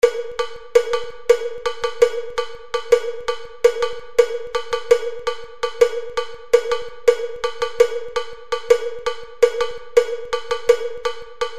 The Bells Page
swing_bell1.mp3